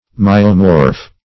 \My"o*morph\